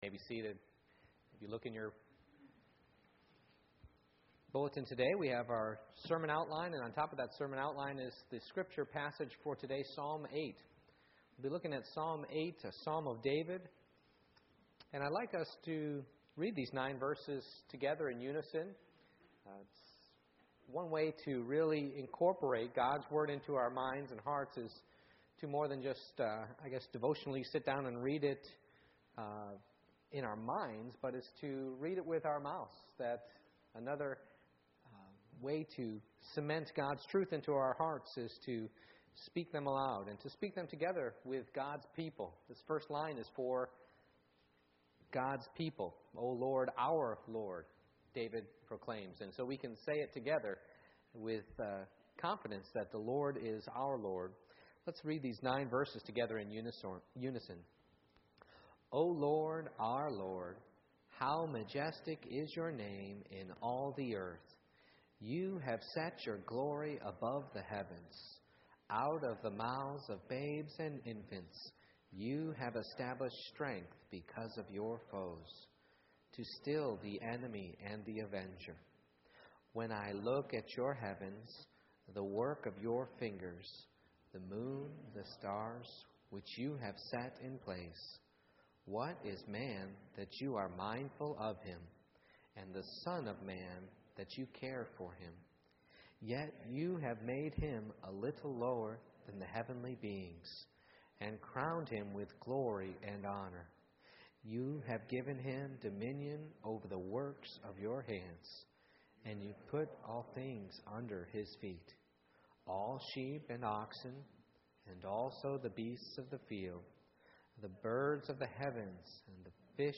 Psalm 8:1-9 Service Type: Morning Worship I. It's all about God's glory